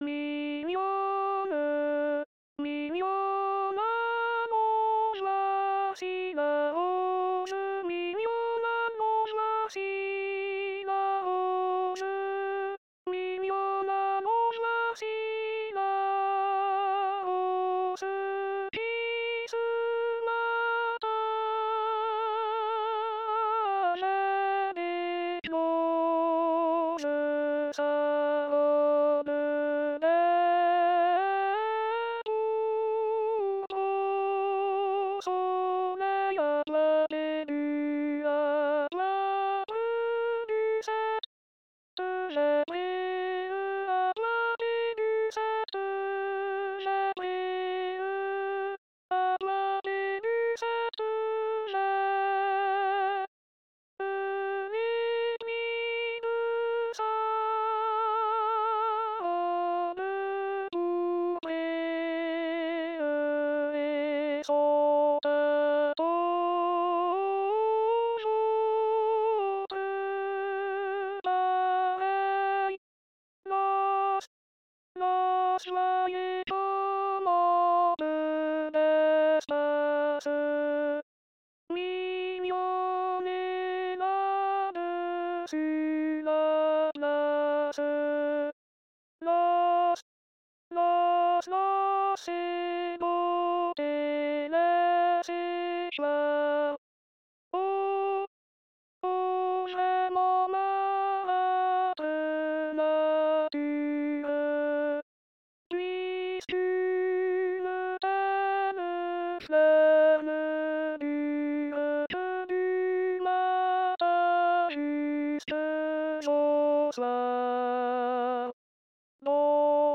costeley_mignonne.alt.mp3